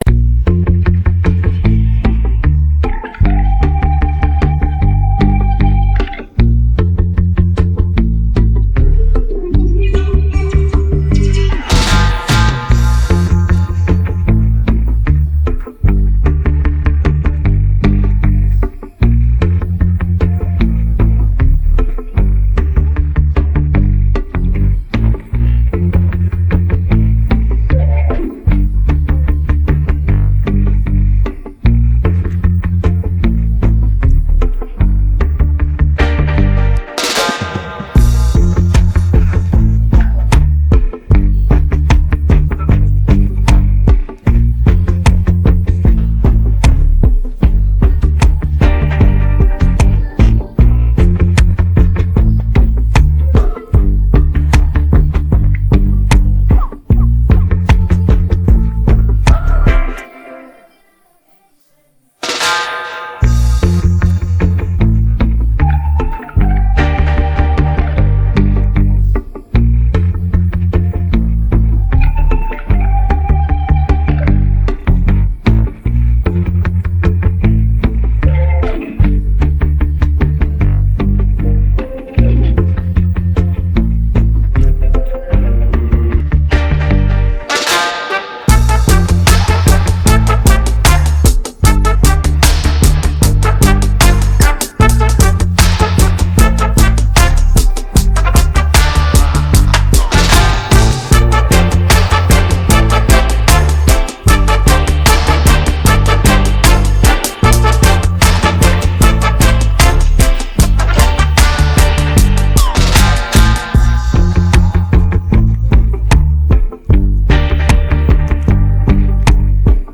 Afro dancehallAfro popAfro-R&B Fusion